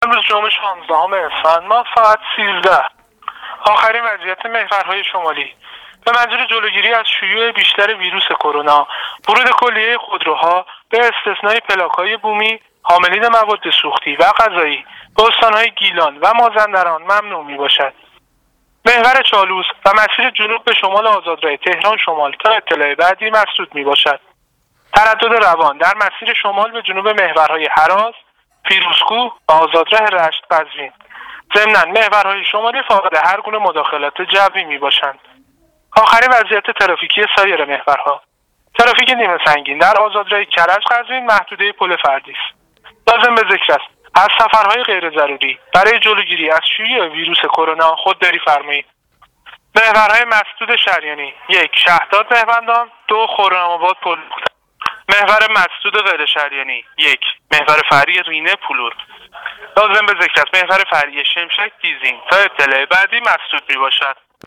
گزارش رادیواینترنتی از آخرین وضعیت ترافیکی جاده‌ها تا ساعت ۱۳ جمعه ۱۶ اسفند ۱۳۹۸: